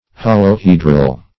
Search Result for " holohedral" : The Collaborative International Dictionary of English v.0.48: Holohedral \Hol`o*he"dral\, a. [Holo- + Gr.